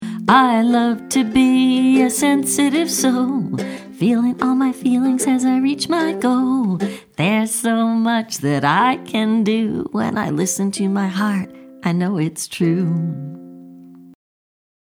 gentle, emotional song